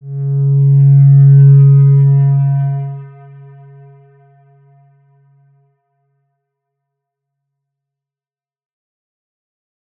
X_Windwistle-C#2-pp.wav